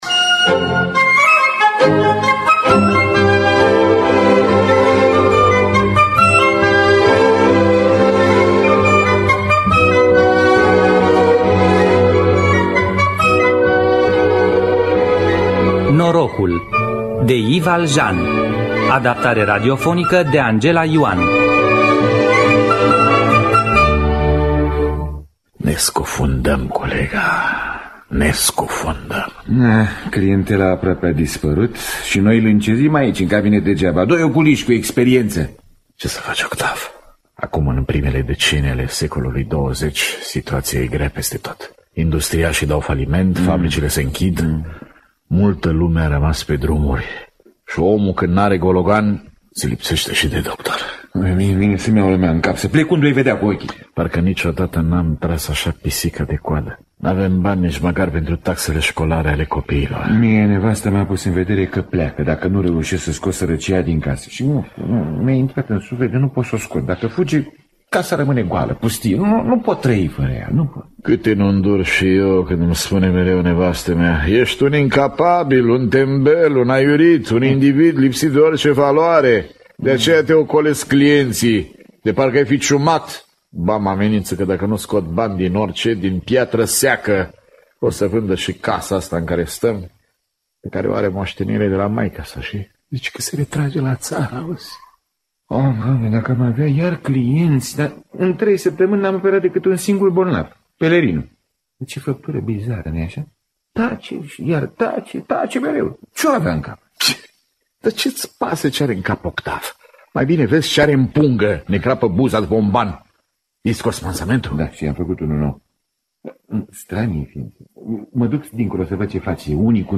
In distributie: George Constantin, Victor Rebengiuc, Adrian Pintea, Marian Mihuţ, Gheorghe Cozorici.